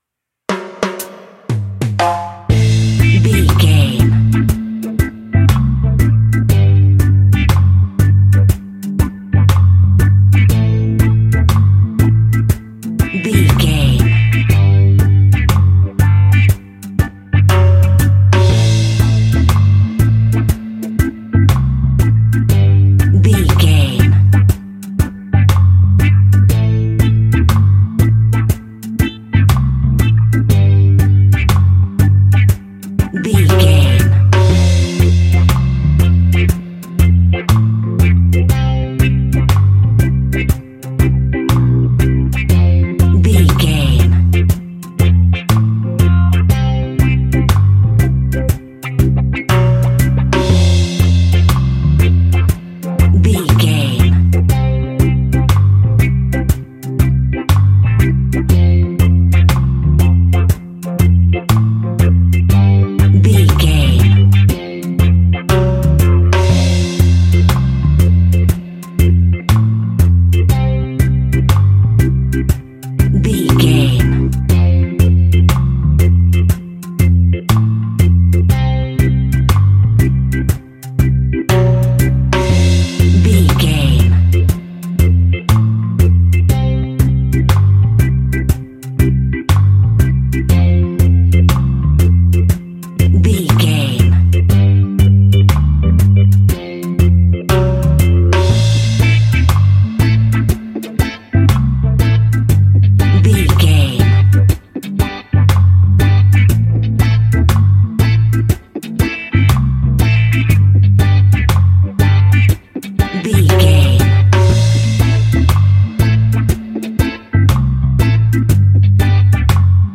Classic reggae music with that skank bounce reggae feeling.
Aeolian/Minor
reggae instrumentals
laid back
off beat
drums
skank guitar
hammond organ
percussion
horns